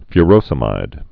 (fy-rōsə-mīd)